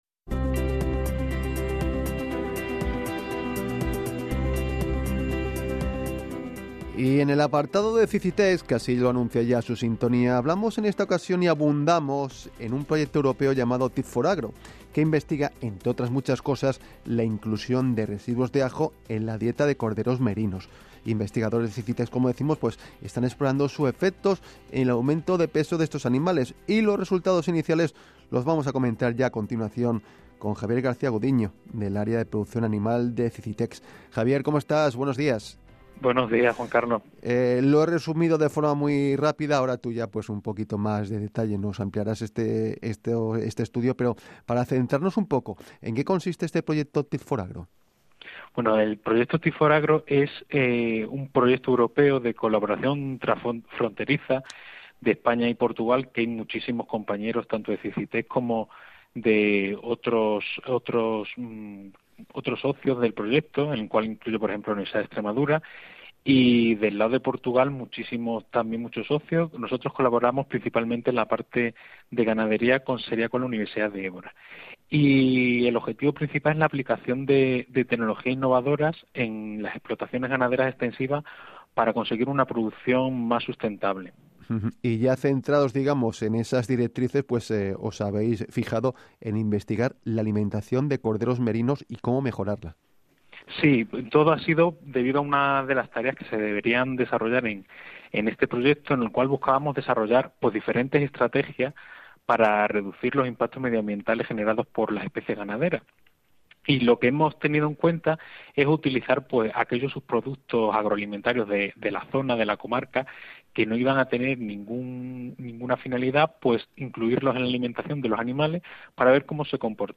El programa de Canal Extremadura Radio El Paliqueo entrevistó